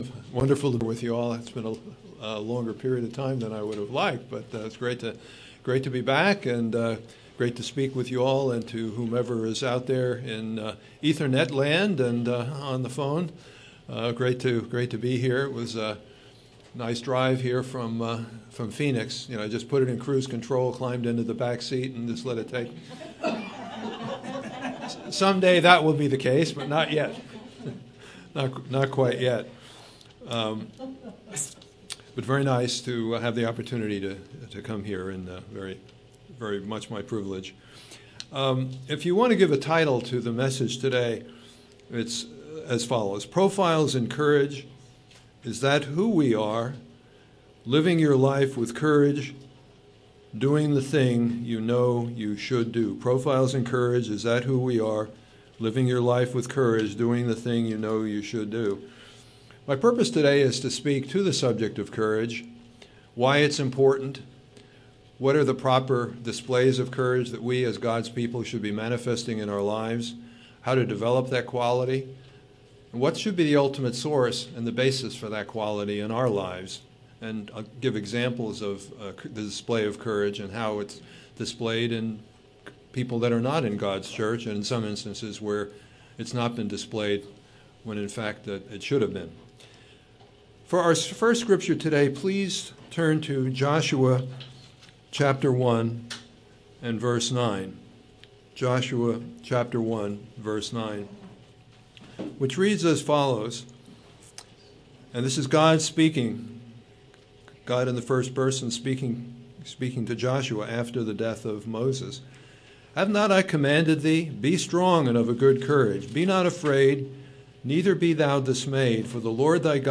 Given in Yuma, AZ